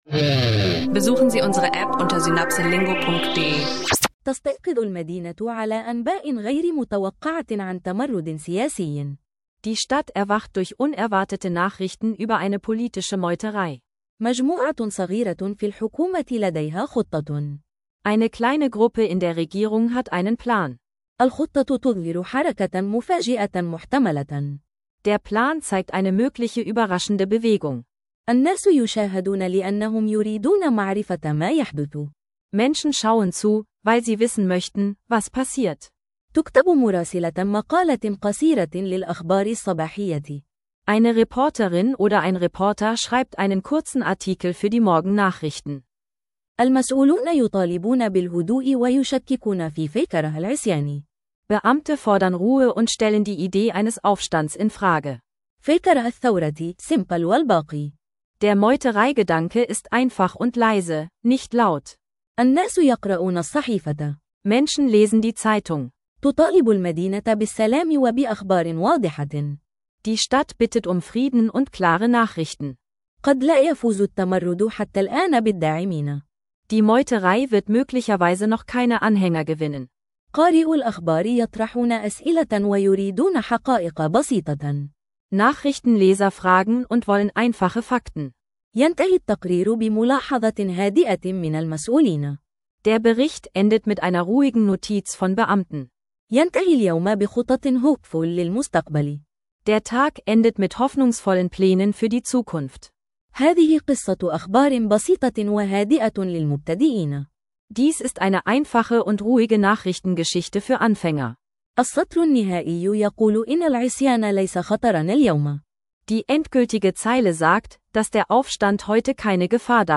Ein ruhiger, klarer Einstieg in Bankwesen, politische Nachrichten und Lotterien – ideal für Arabisch-Anfänger